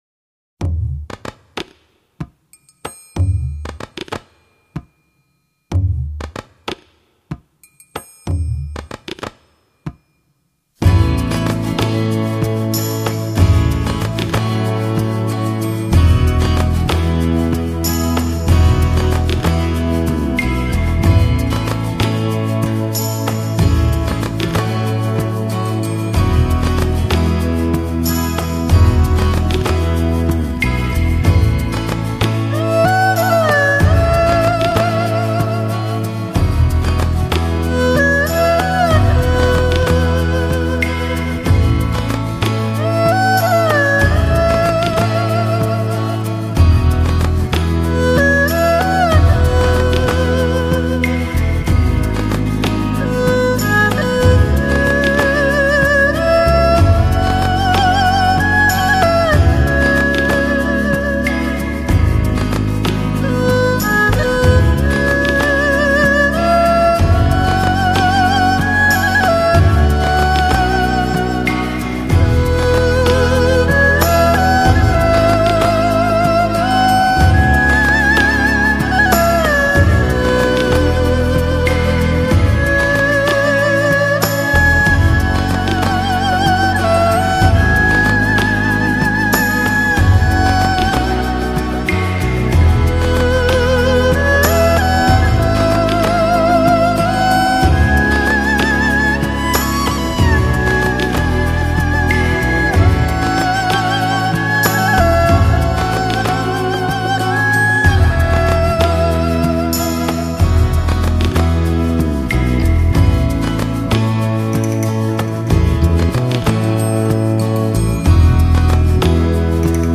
东洋の神秘と西洋のきらめきが融合したヒーリング?コンピレーション?アルバム第3弾
超越了时空 慈祥的声音在回响